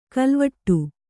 ♪ kalvaṭṭu